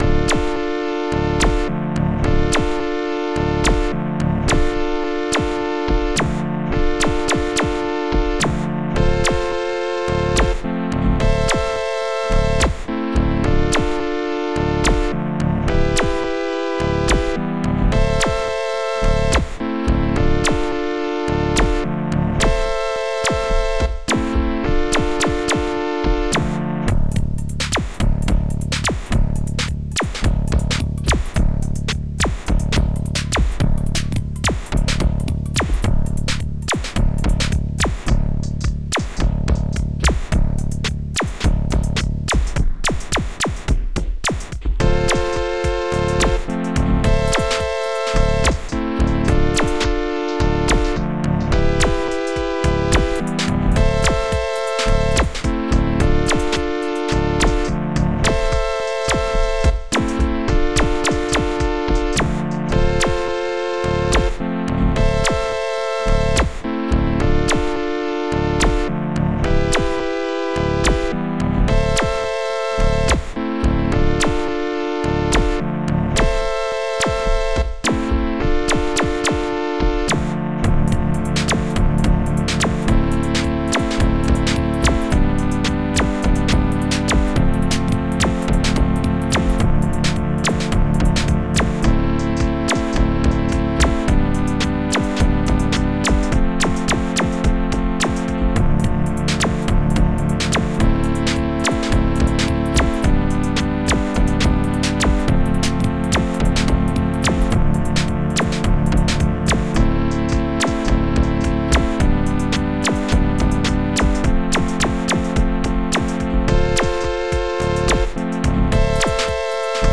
Protracker and family
akkord-dur
akkord-moll
Bassguitar